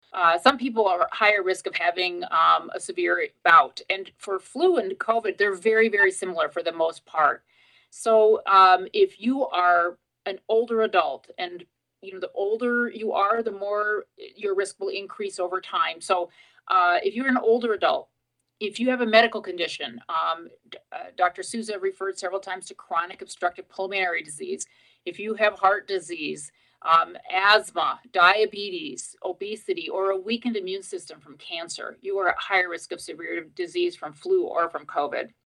During a press availability, agency officials called the situation a ‘triple-demic’ of flu, respiratory syncytial virus (RSV), and COVID-19.